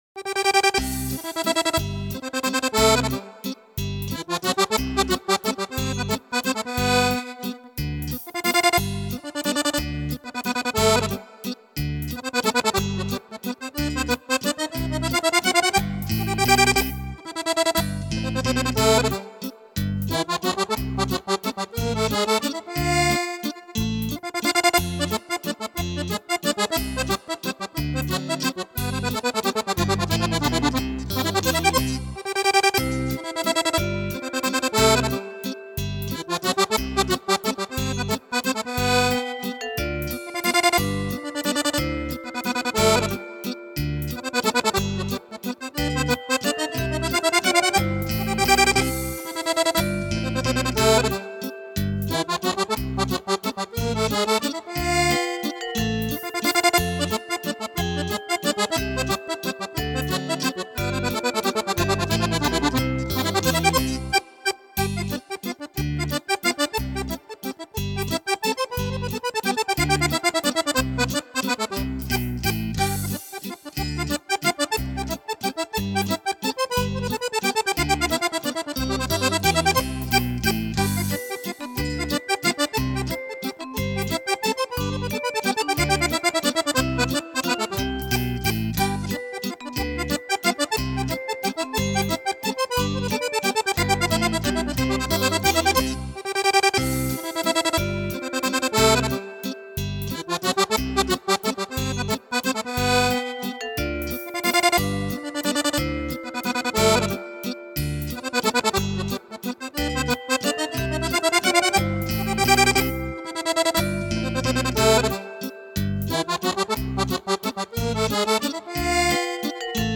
Valzer
16 brani per fisarmonica incisi dalle orchestre